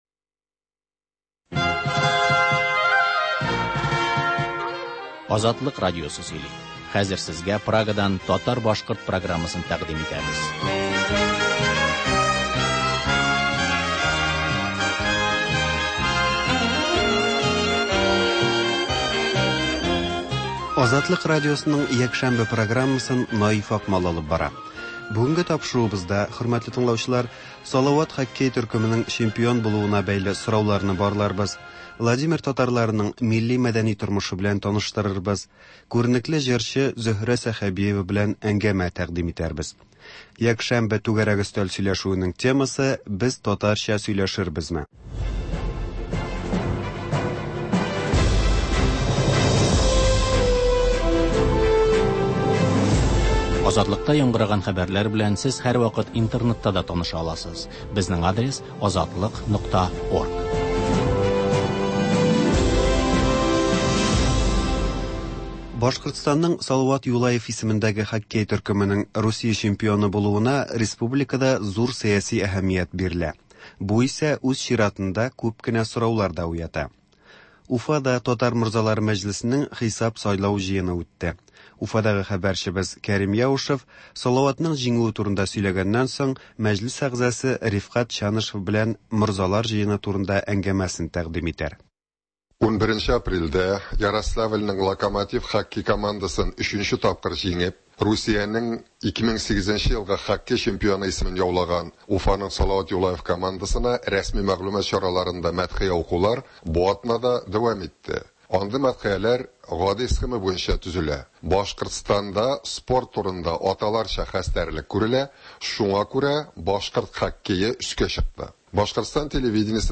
сәгать тулы хәбәр - Башкортстаннан атналык күзәтү - түгәрәк өстәл артында сөйләшү